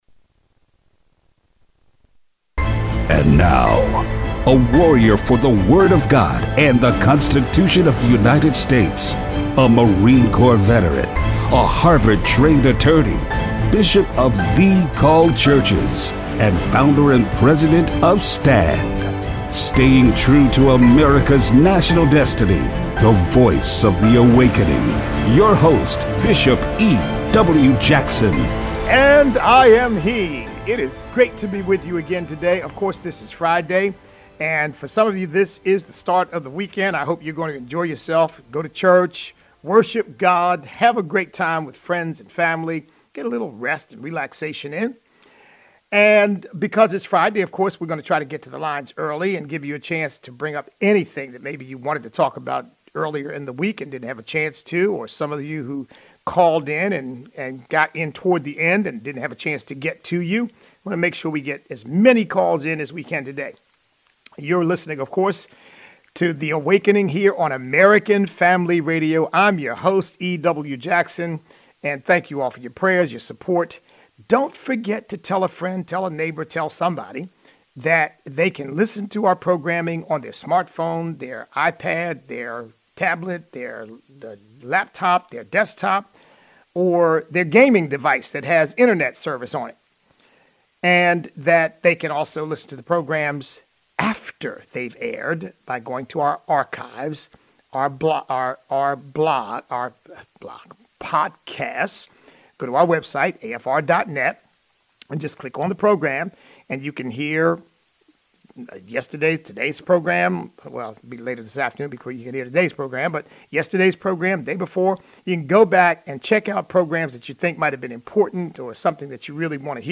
Show Notes Listeners call in to discuss the topics of the day.